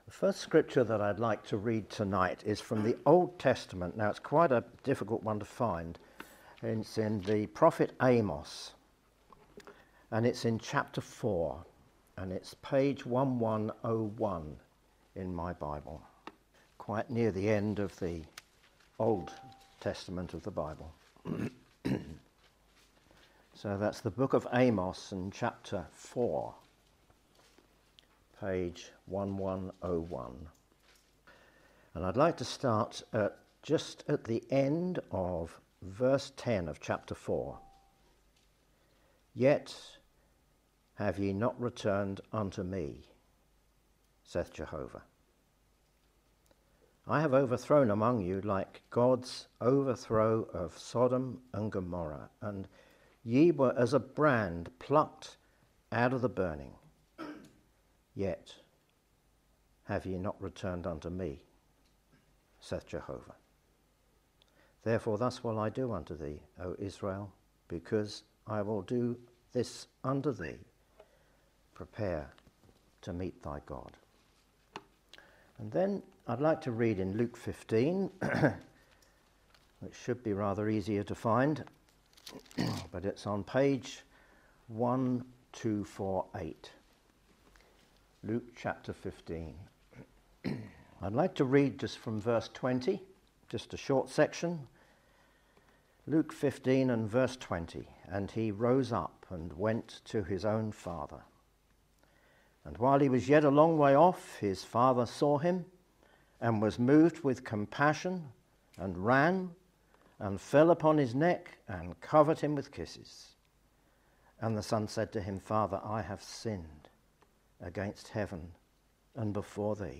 People make all sorts of preparations in life—education, careers, finances—but how prepared are you to meet God? This preaching asks the most important question of all, urging you to consider your readiness to meet God.
Gospel Preachings